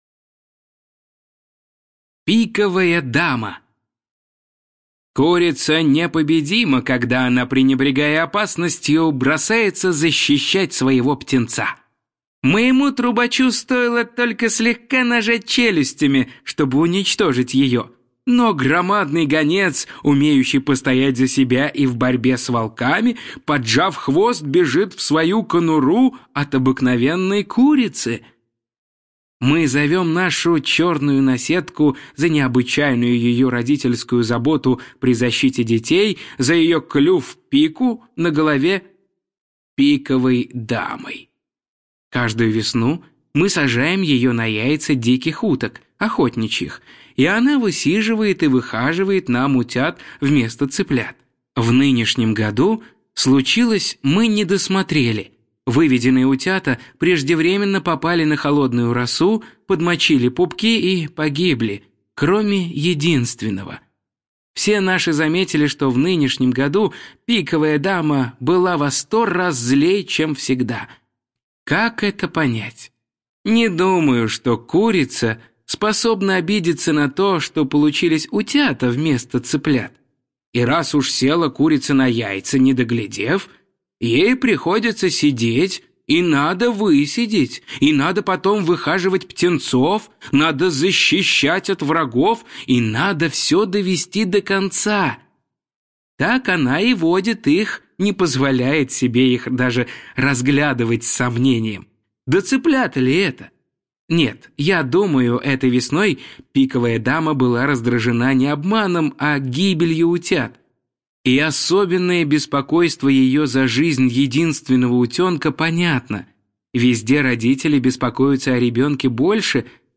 Пиковая дама – Пришвин М.М. (аудиоверсия)
Аудиокнига в разделах